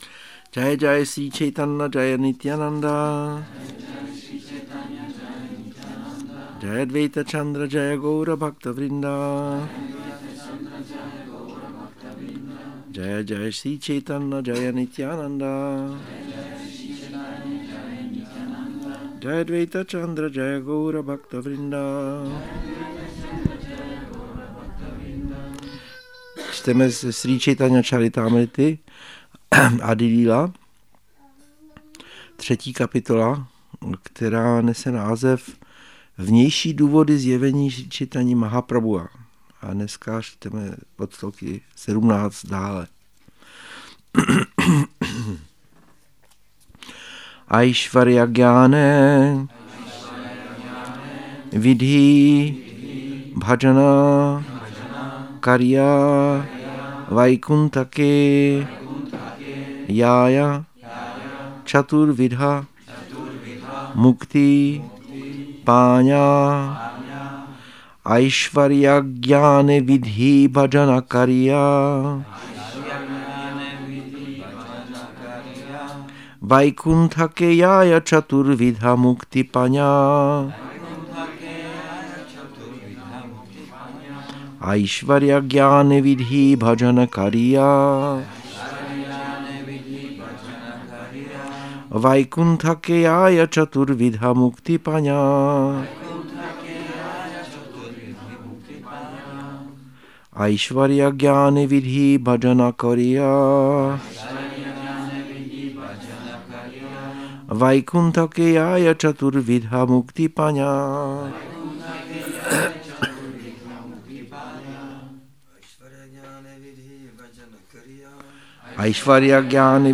Přednáška CC-ADI-3.17 – Šrí Šrí Nitái Navadvípačandra mandir